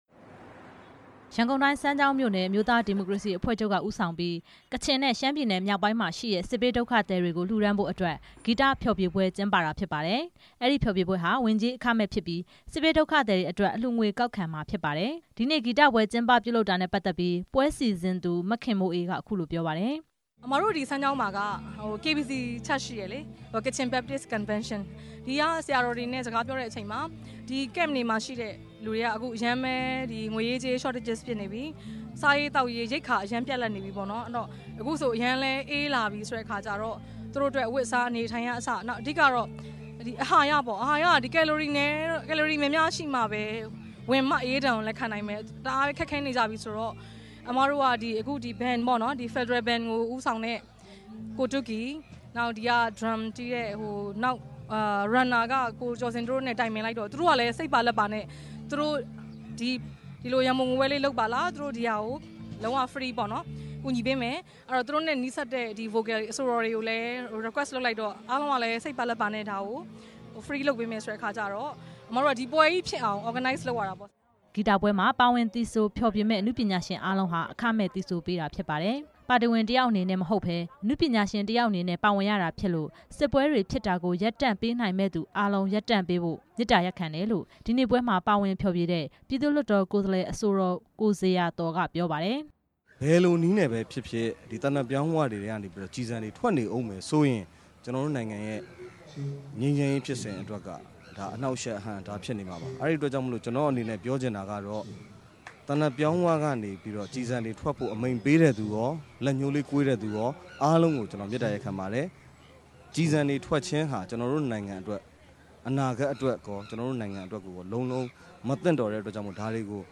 RFA သတင်းထောက်